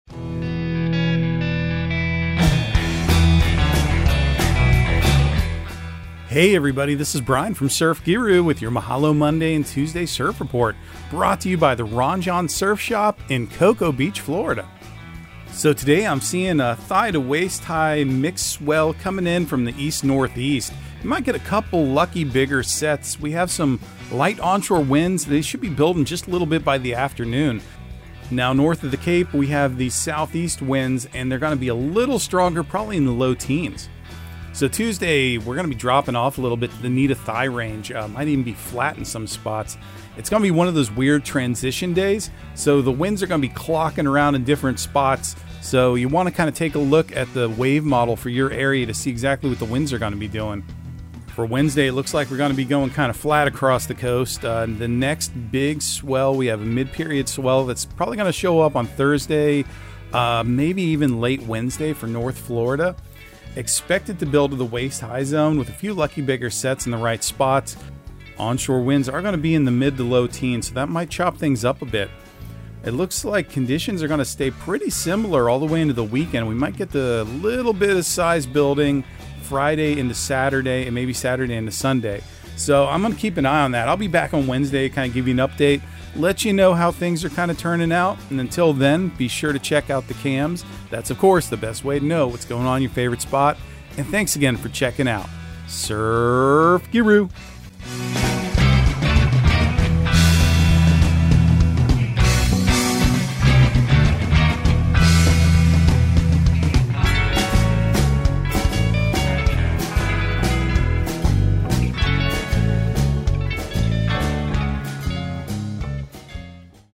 Surf Guru Surf Report and Forecast 05/08/2023 Audio surf report and surf forecast on May 08 for Central Florida and the Southeast.